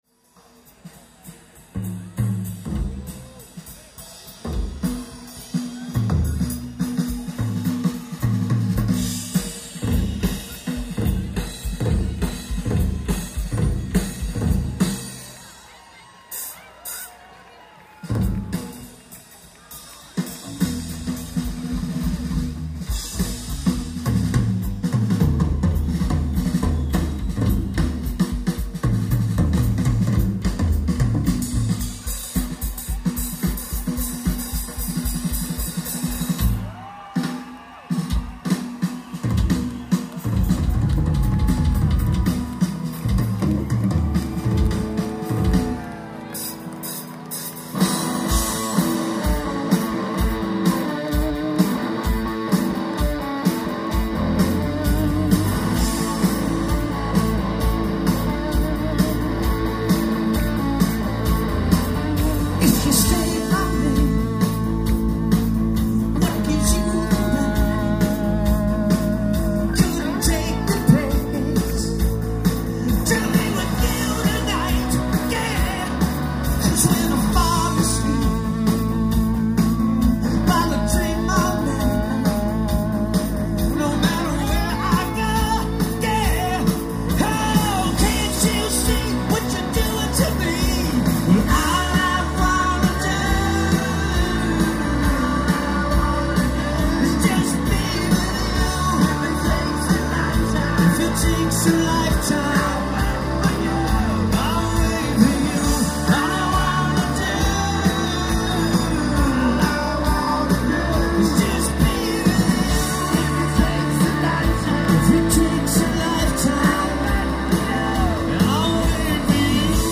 Source: Audience Digital Master